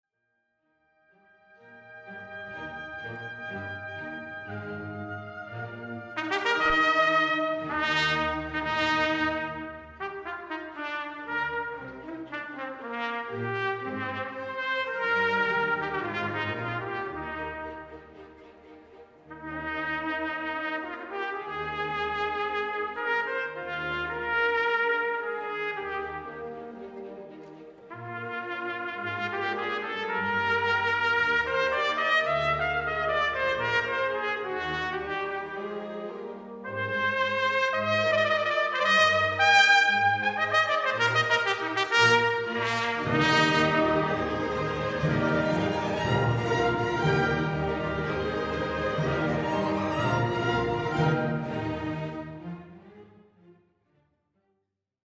ENSEMBLE ORCHESTRAL SYMPHONIQUE
concerto trompette